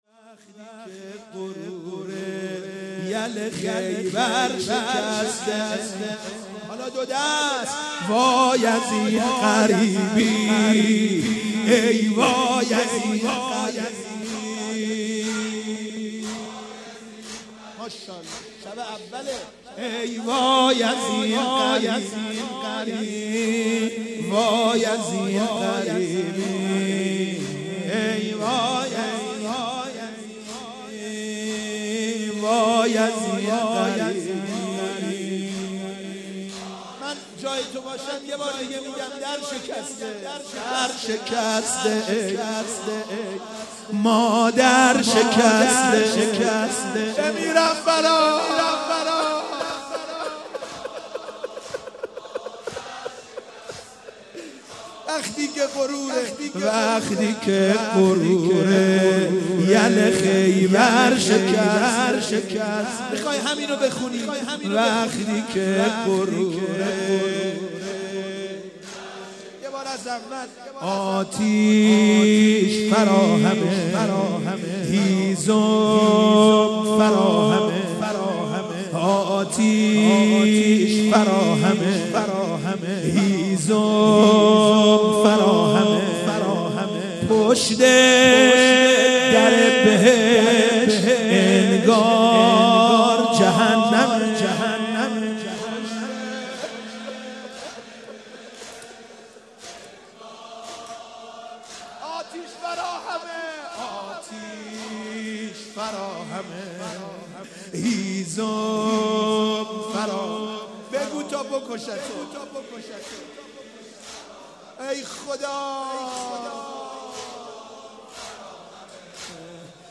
مداح
مناسبت : شهادت حضرت فاطمه زهرا سلام‌الله‌علیها
قالب : زمینه